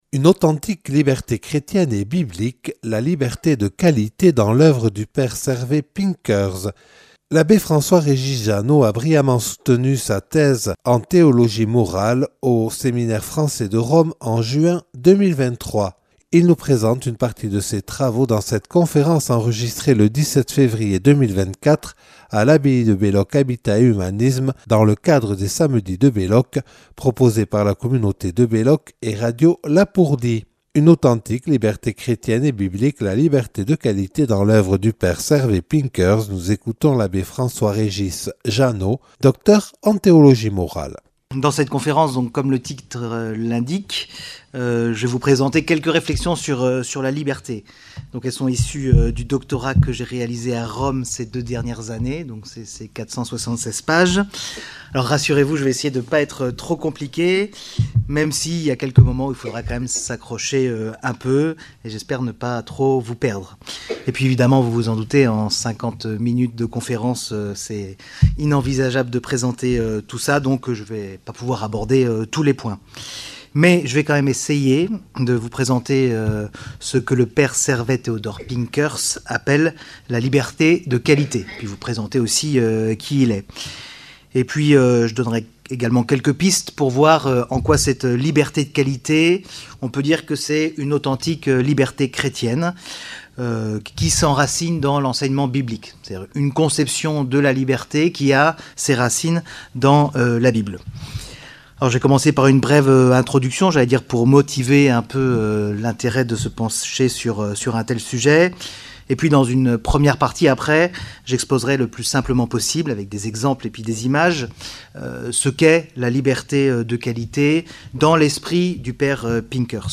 (Enregistrée 17/02/2024 lors des Samedis de l’abbaye de Belloc).